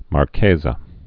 (mär-kāzə, -kĕzä)